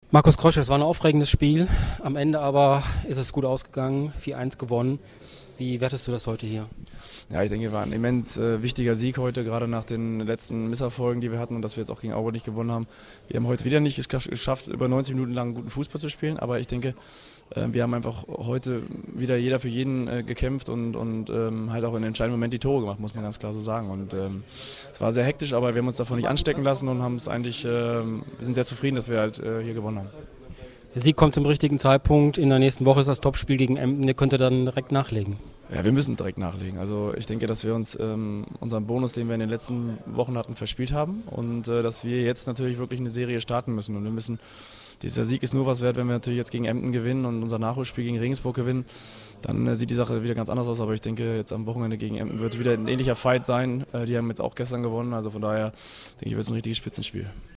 Interview: Kapitän Markus Krösche zum Spiel
Erfurt_Kroesche.mp3